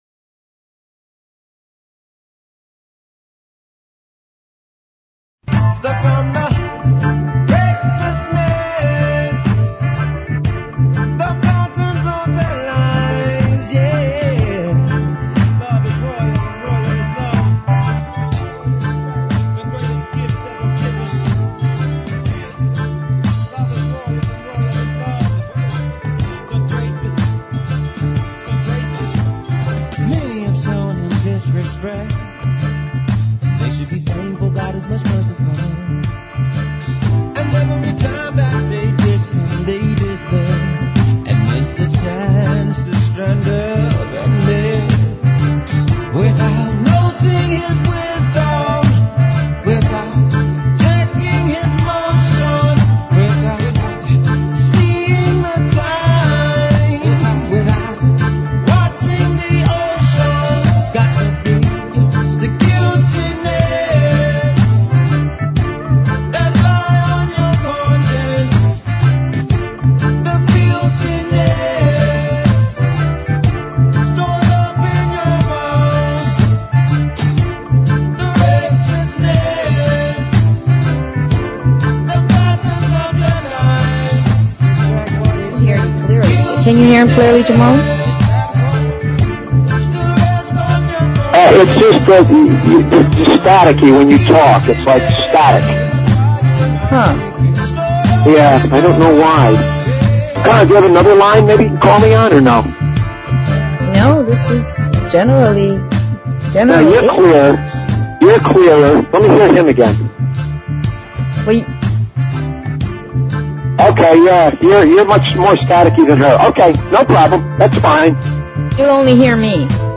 Talk Show Episode, Audio Podcast, Sovereign_Mind_Radio and Courtesy of BBS Radio on , show guests , about , categorized as